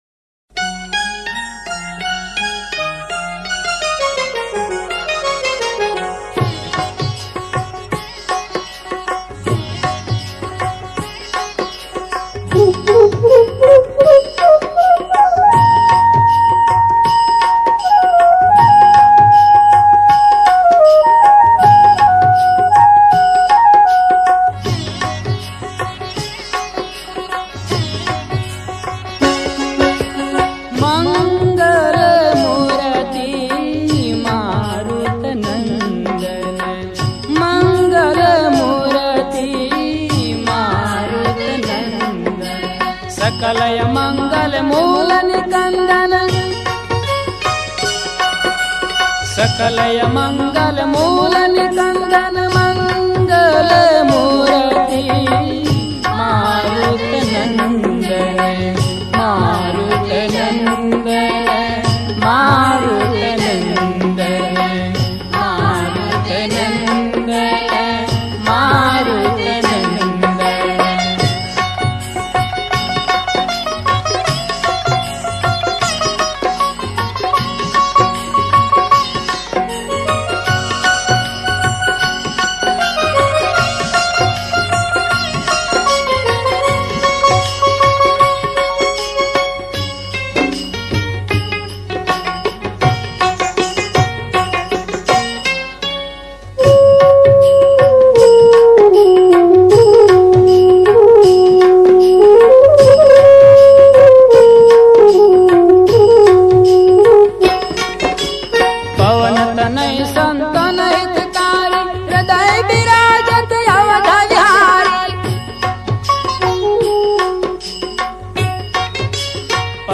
Devotional Songs > Shree Hanuman Bhajans